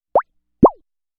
watersplash1426.mp3